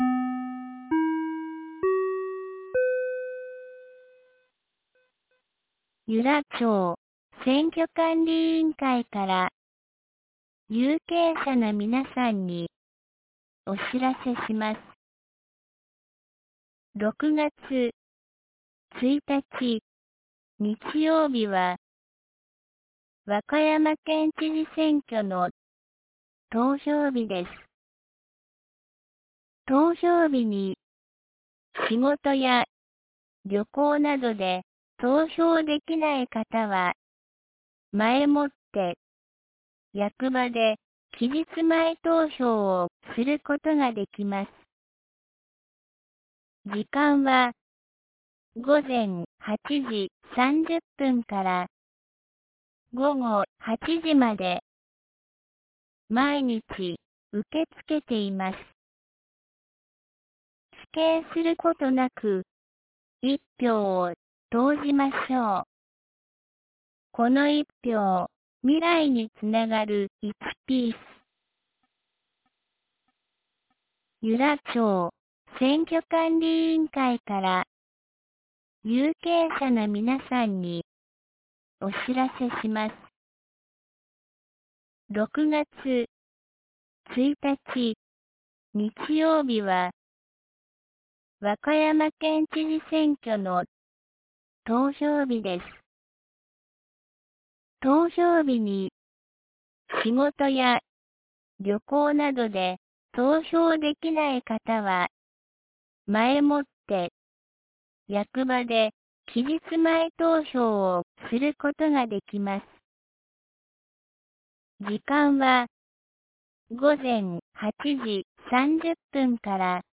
2025年05月21日 08時03分に、由良町から全地区へ放送がありました。